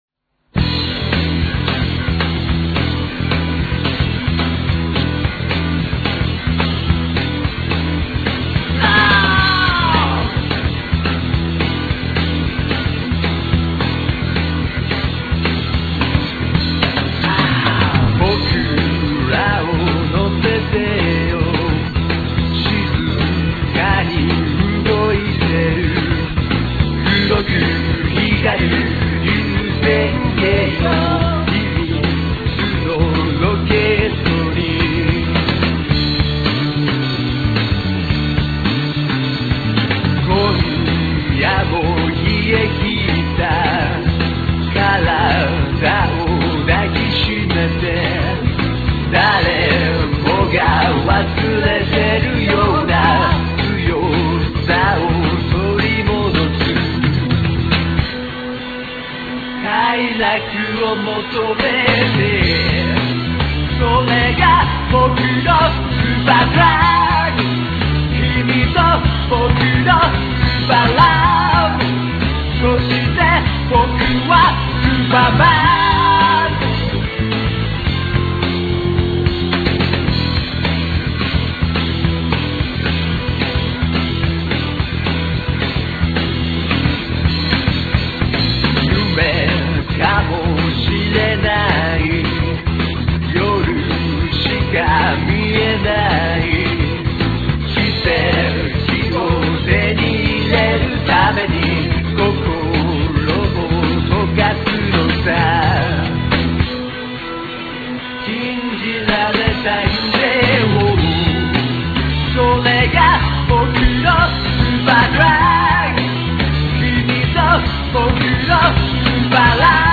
シンプルなメロディーのサビは売れセン狙いか？